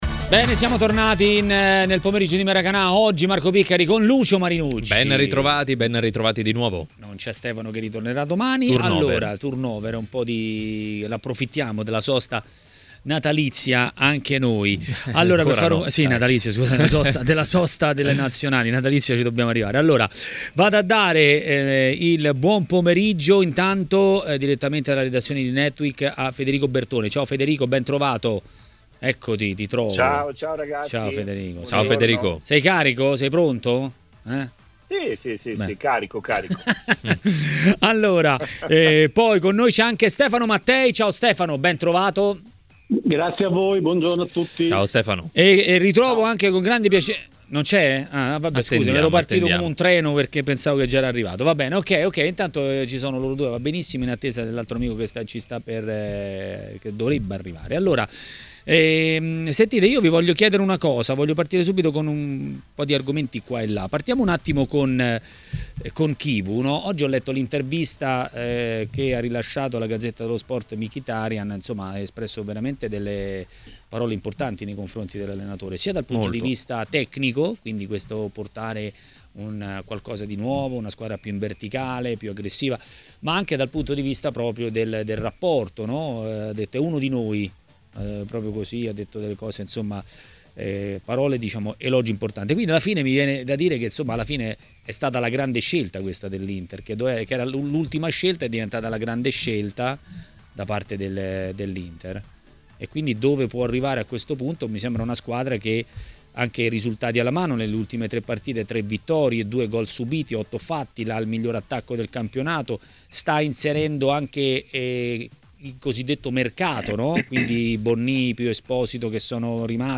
è intervenuto a TMW Radio, durante Maracanà.